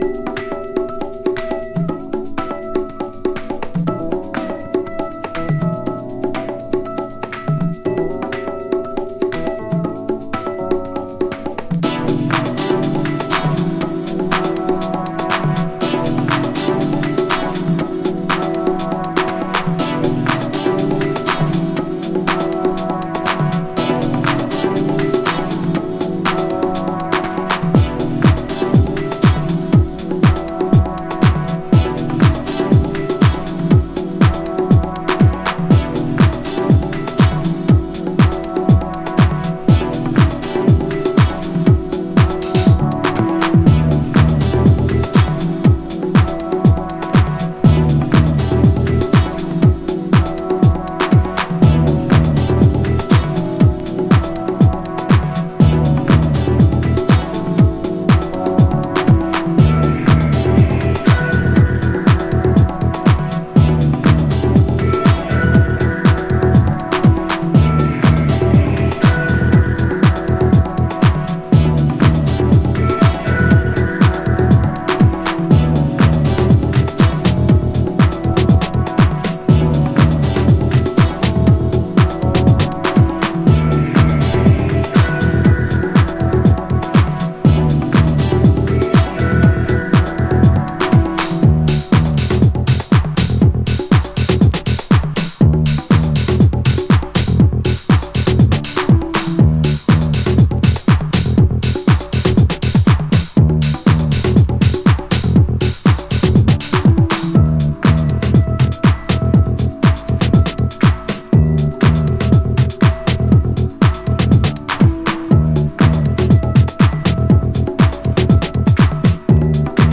House / Techno
ハードコアな現場を潜り抜けてきたからこそ出せる、オルタナティブなブギーグルーヴを纏ったUKディープハウス。